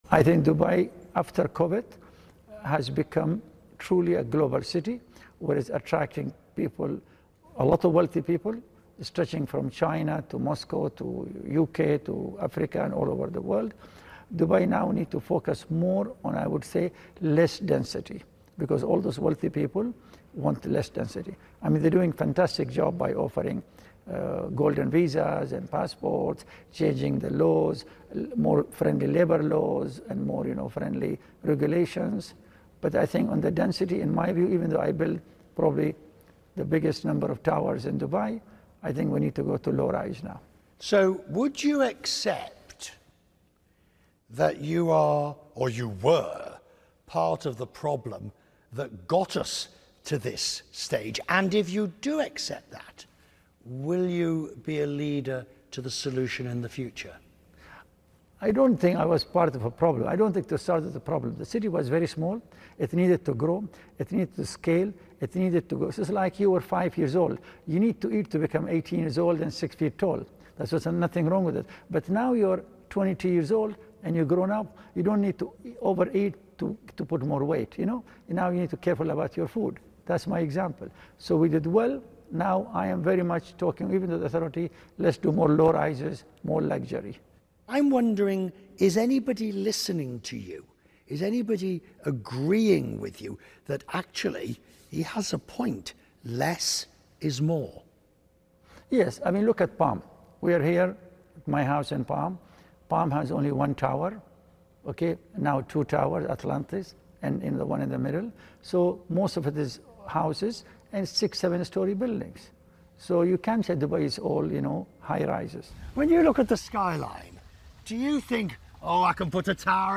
In a wide-ranging interview, DAMAC Properties Founder Hussain Sajwani sits down with CNN anchor Richard Quest and talks about the development of Dubai, impacts of Covid19 on real estate and and lessens learned from 2009 financial crises.